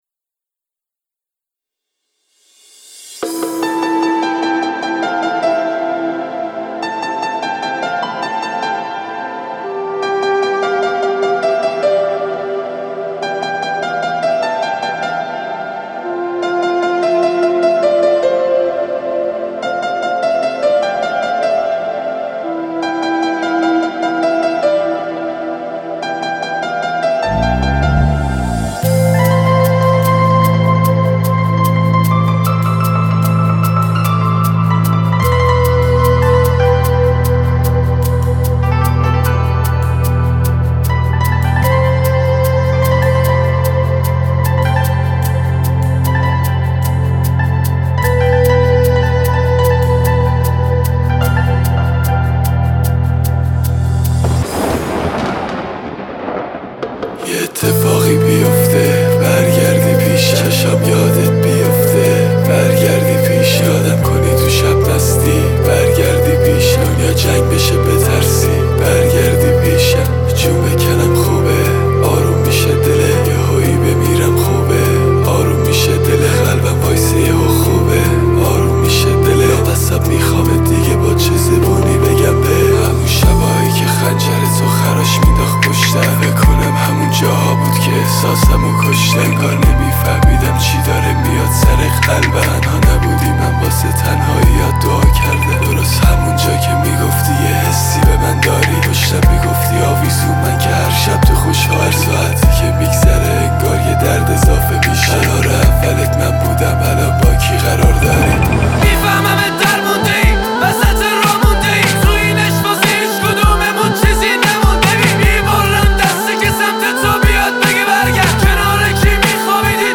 دیس لاو